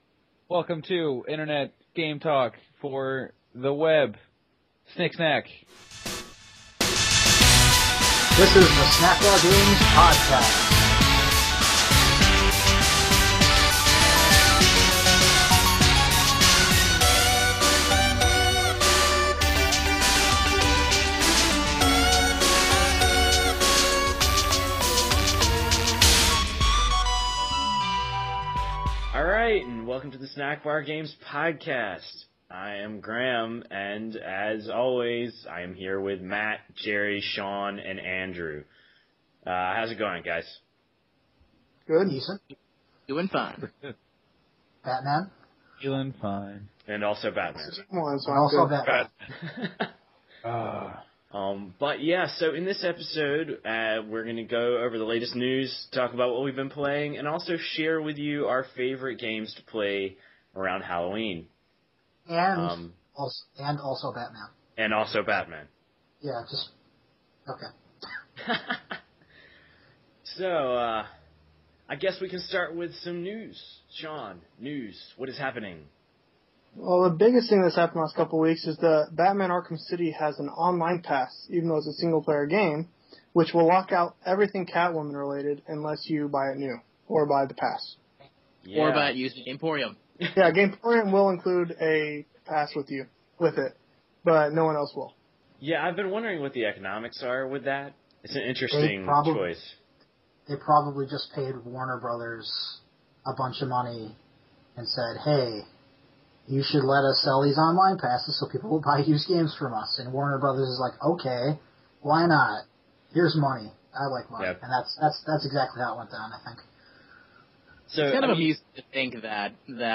In this super-sized episode, the crew talks about favorite games to play on Halloween, discusses the now-dated Vita launch and groans when someone says they haven’t played a game they like.